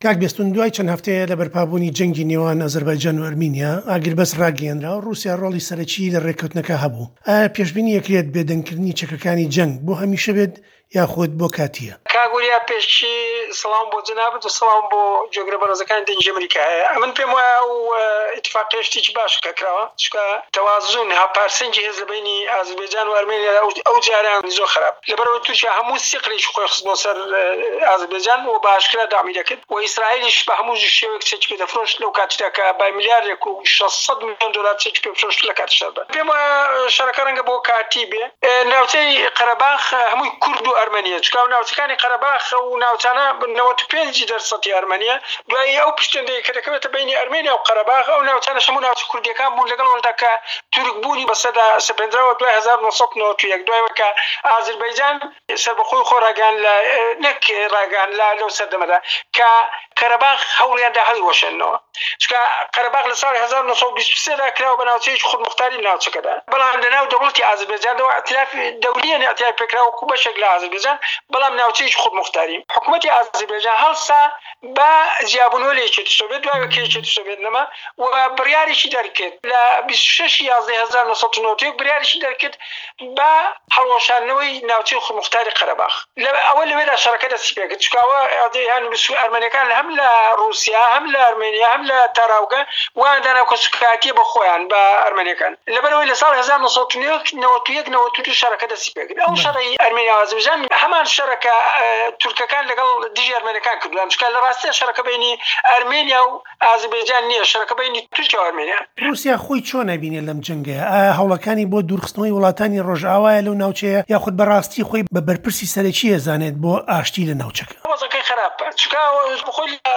زانیاری زیاتر لەدرێژەی وتووێژەکەدایە